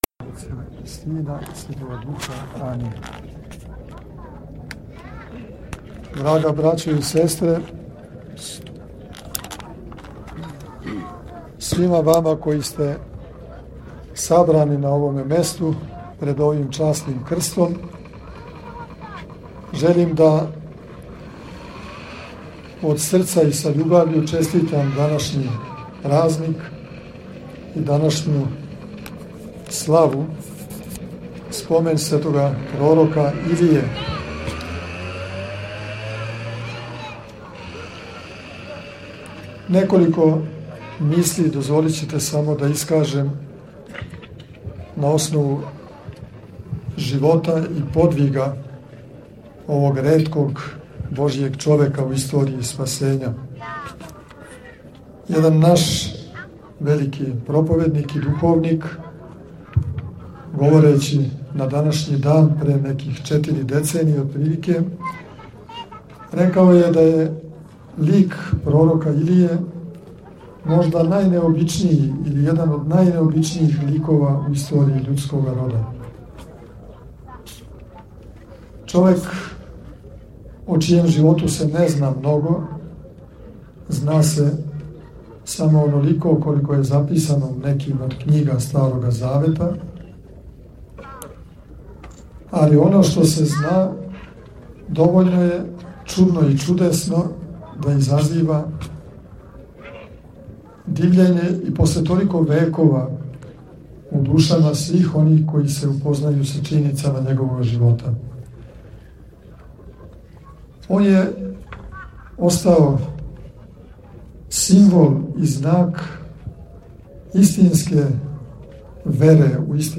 У центру села, испред Часног Крста Господњег, служено је вечерње богослужење, освештано славско кољиво и пререзан славски колач.
После вечерње службе, Његово Преосвештенство Епископ бачки Господин др Иринеј обратио се бираним речима верном народу упитивши им речи поуке и честитајући сеоску славу.